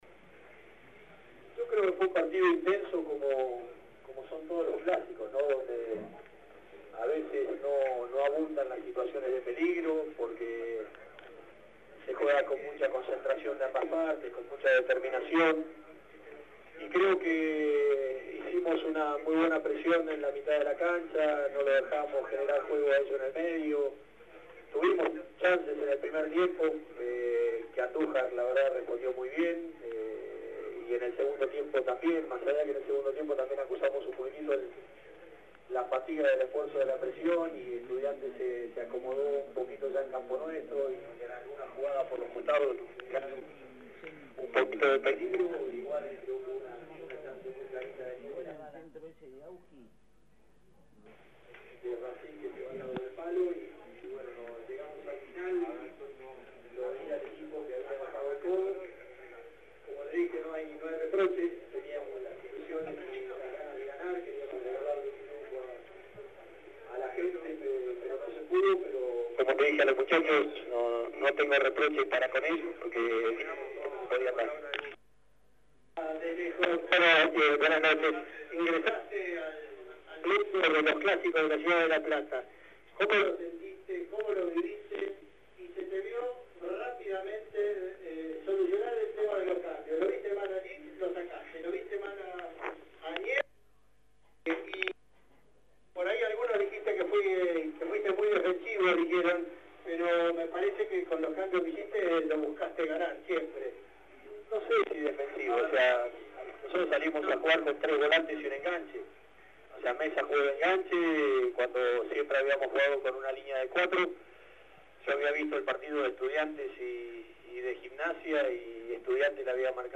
Conferencia-Alfaro.mp3